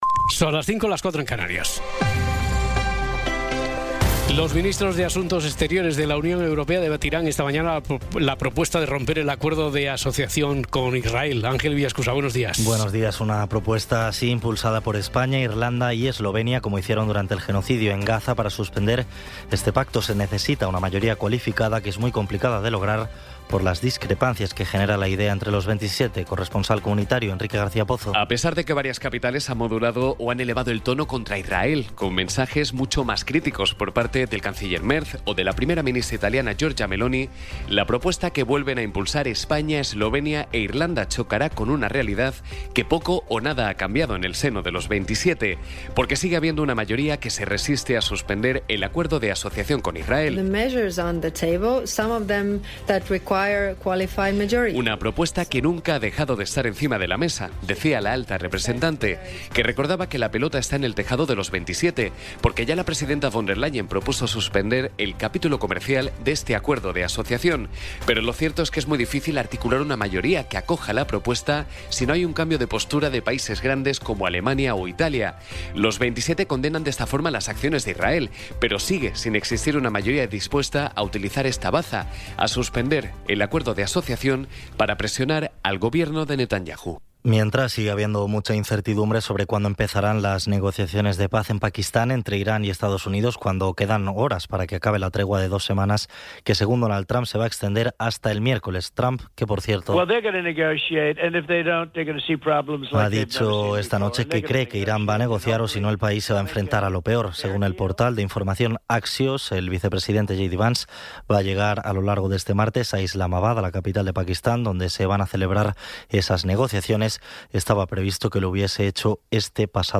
Resumen informativo con las noticias más destacadas del 21 de abril de 2026 a las cinco de la mañana.